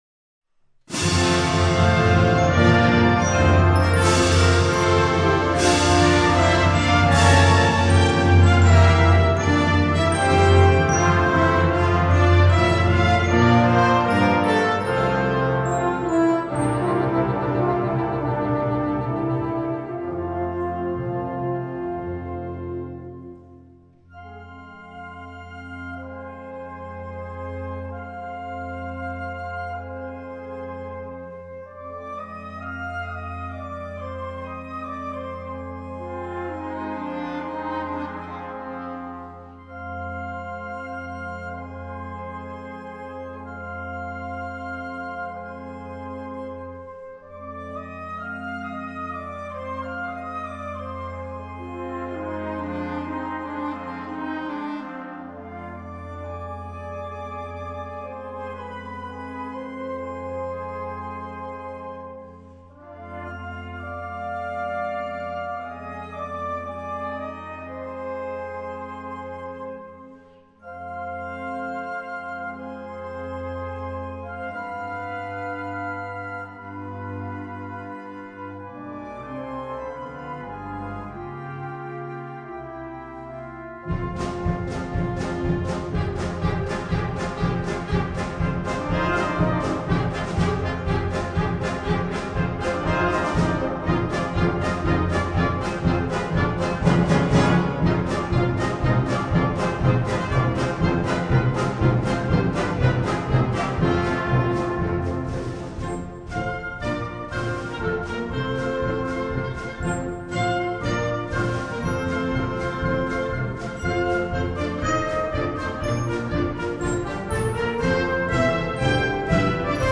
Besetzung: Blasorchester
(with opt. Soprano Voice)